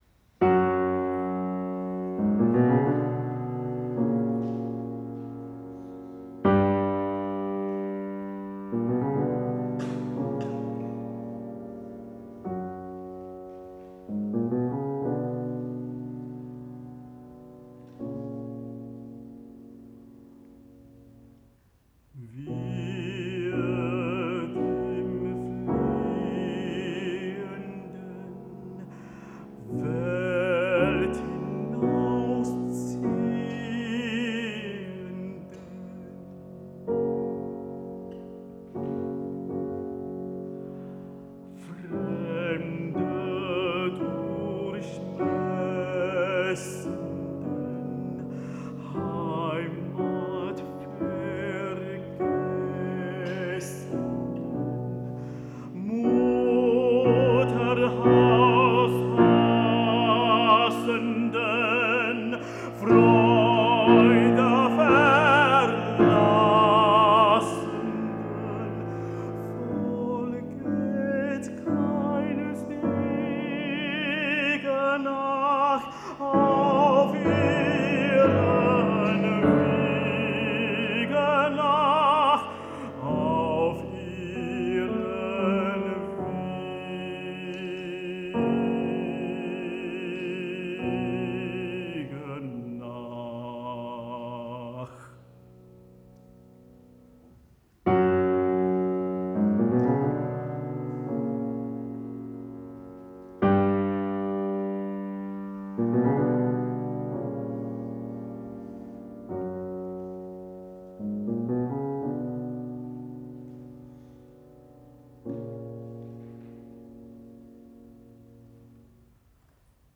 Tenor
Piano
A pair of Neumann U87Ai as main pair